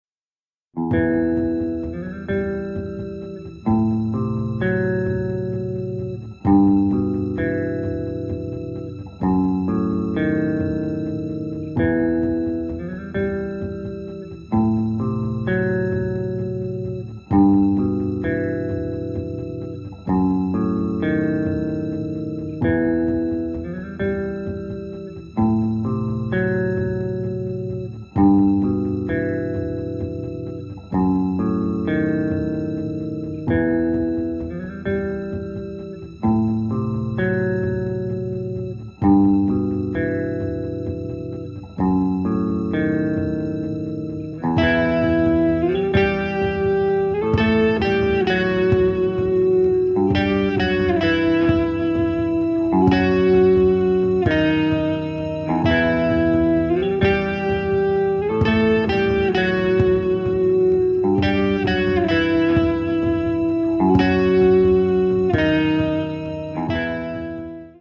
• Качество: 320, Stereo
гитара
душевные
грустные
без слов
из игры
Саундтрек из меню игры